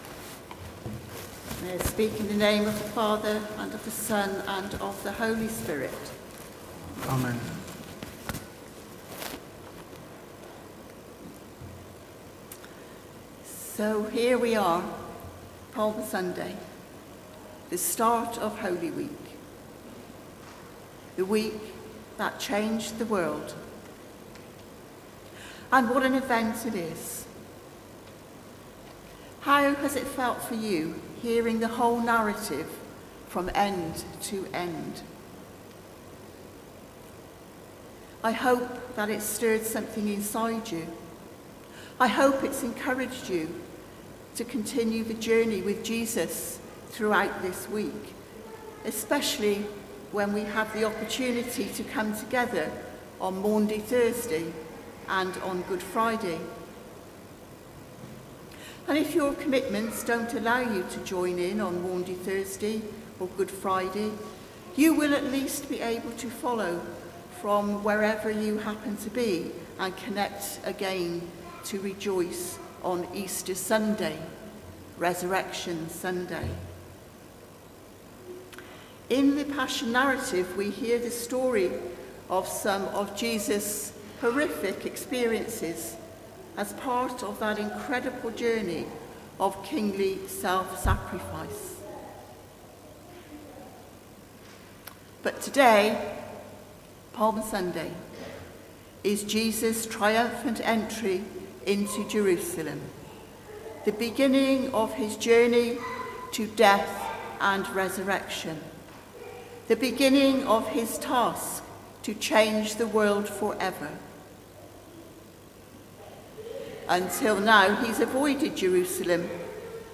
Sermon: Your Holy Week | St Paul + St Stephen Gloucester